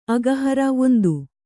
♪ agahara